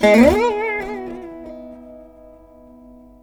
148B VEENA.wav